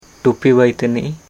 [ʈupi bai taniʔ] noun hat-maker